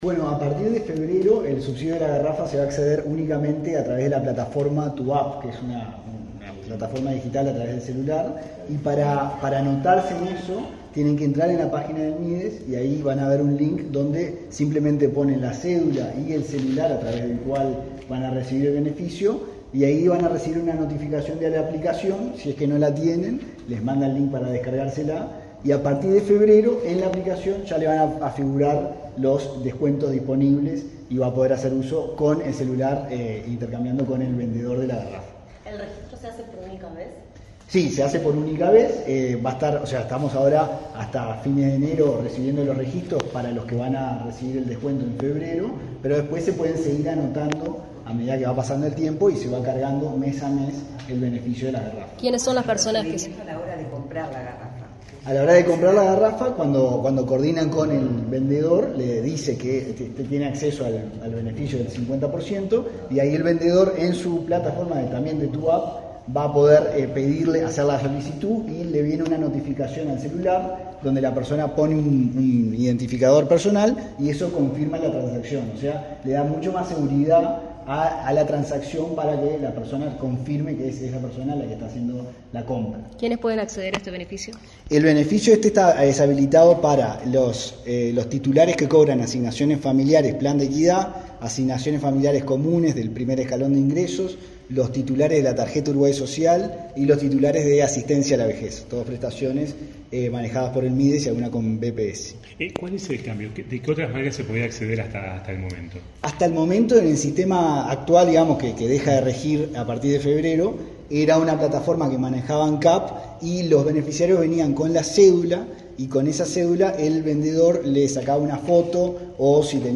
Declaraciones de Antonio Manzi del Mides
El director nacional de Transferencias y Análisis de Datos del Ministerio de Desarrollo Social de Uruguay (MIDES), Antonio Manzi, informó a la prensa